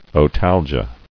[o·tal·gia]